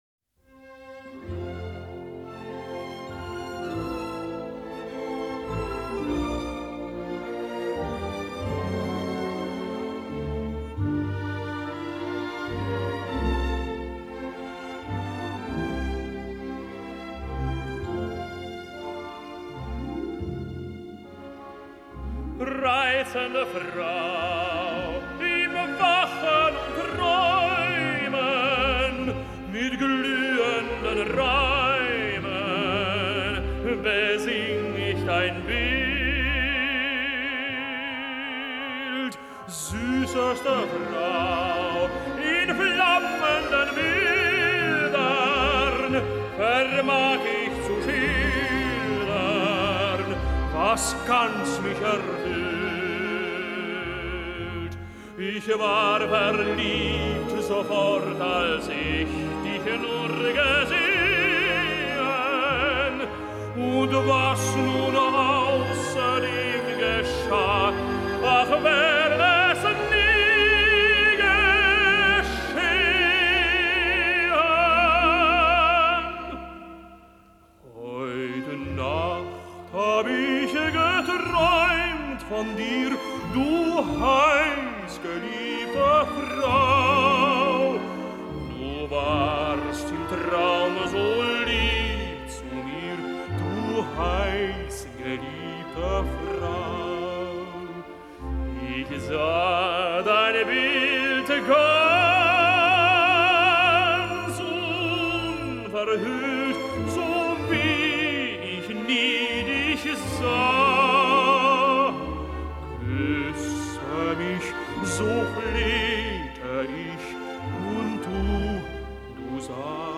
Только это,  по-моему, из "Княгини чардаша" ("Сильвы"), Эдвин поет в первом действии: "Reizende Frau..."